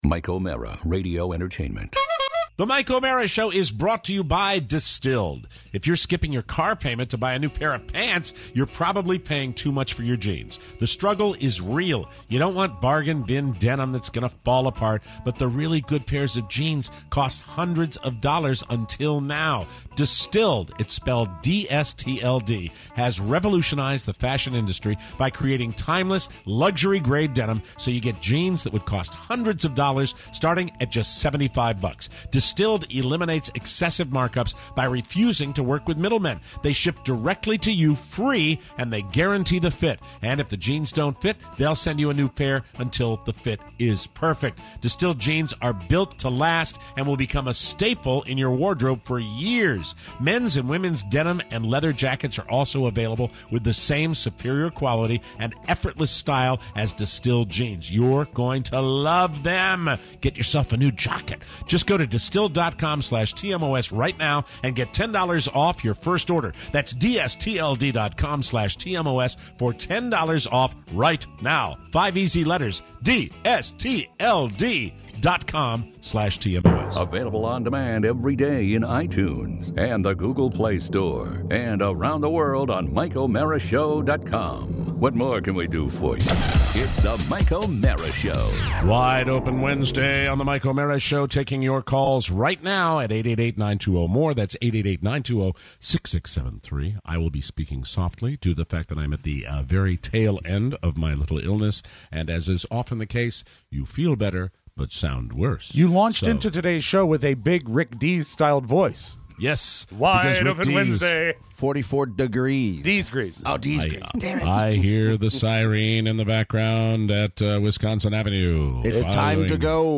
Your fantastic calls!